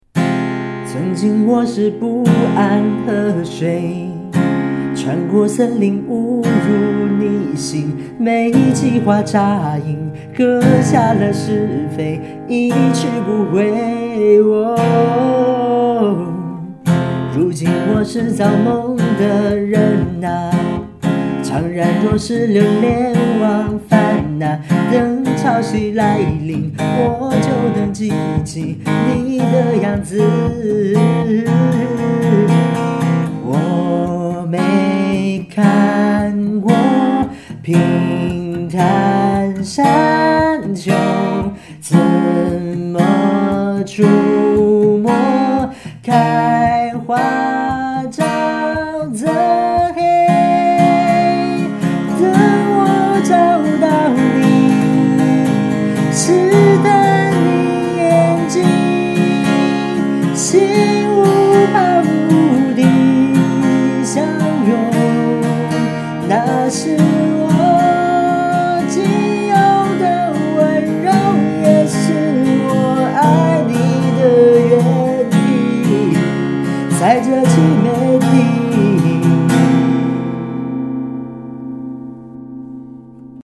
变调夹/指法：1品G调指法
采用G调指法编配，是一首典型的4/4拍歌曲，主歌部分用扫弦开放音和闷音相结合的伴奏形式，给律动增添了一丝色彩